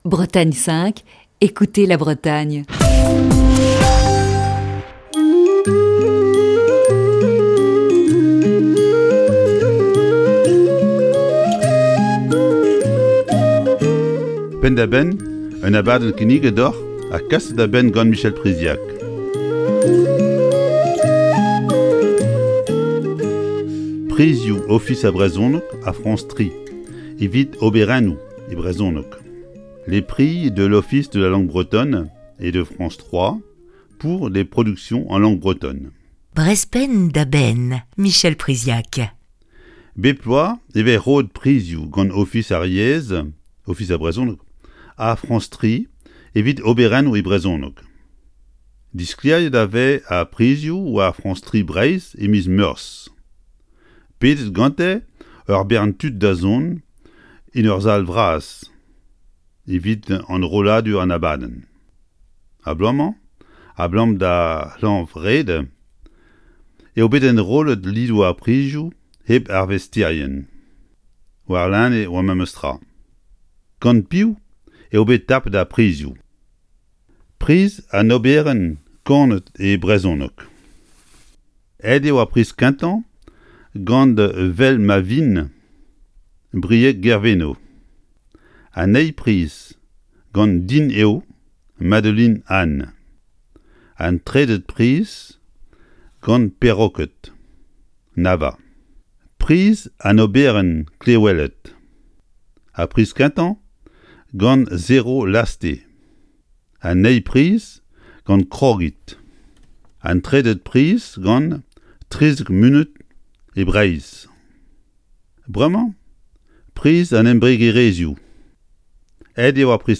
Chronique du 9 avril 2021.